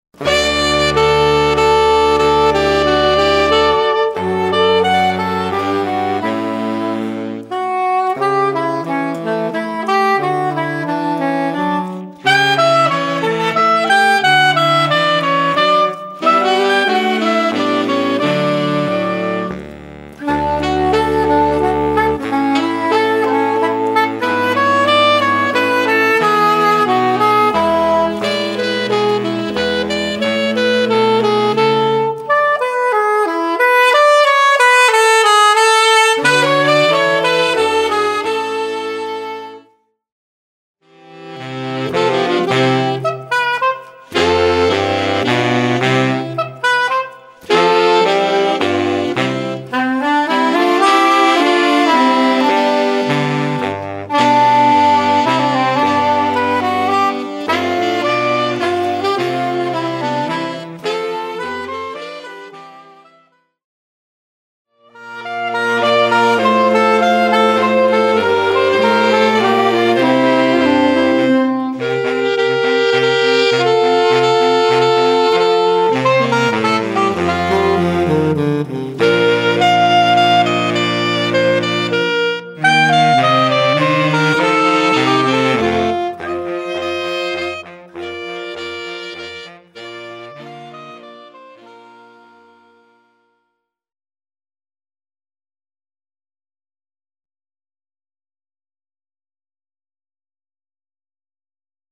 Quintett